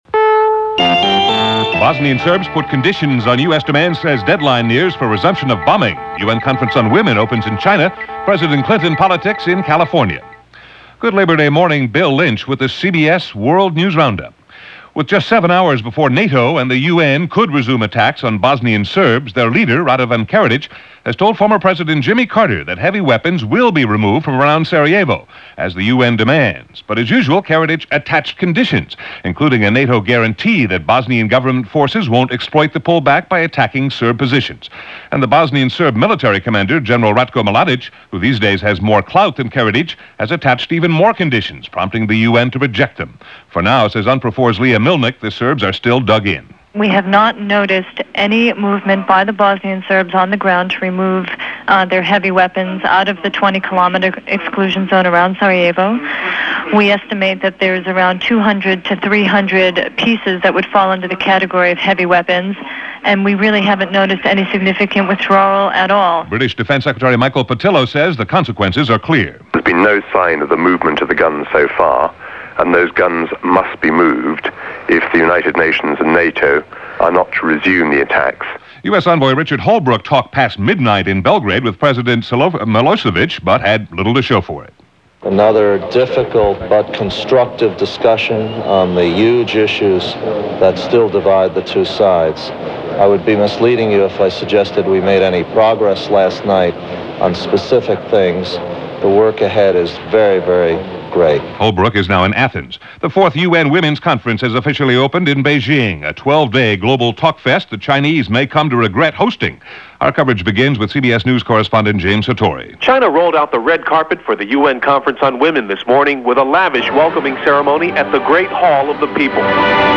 All that, and a lot more for this September 4th in 1995 as presented by The CBS World News Roundup.